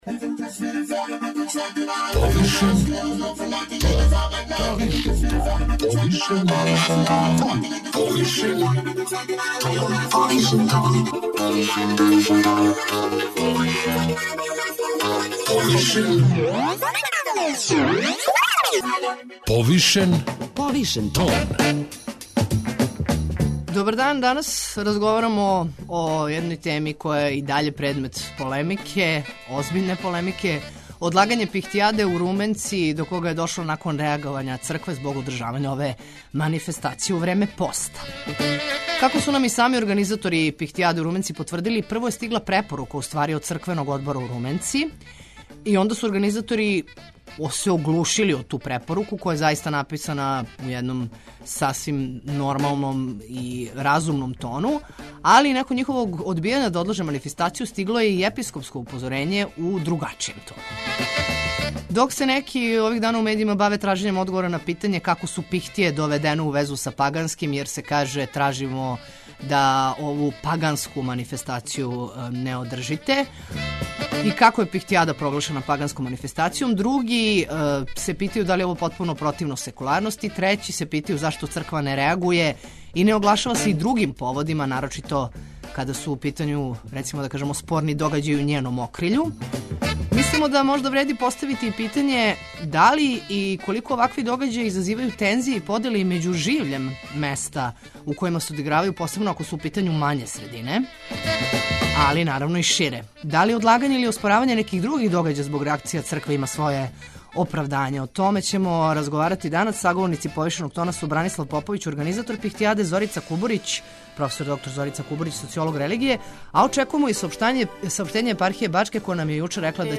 Саговорници